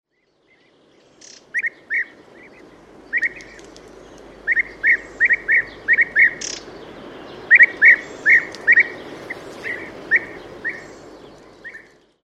FÅGELSÅNG
Inspelningarna är gjorda med digital teknik Bandspelare: Sony MD MZ-R700; Sharp MD-MT 90; Sony HI MD MZ-RH10 och MZ-RH 1. OLYMPUS LS 10:mikrofon: Audio-Technica Pro 24; Sony ECM-MS907; Telimga Pro 5 Stereo Dat. Hörlurar Sennheiser parabolförstärkt (Telingaparabol)
Biätare Merops apiáster Inspelad på Anacapri april 2008
Merops apiastercopy (2).mp3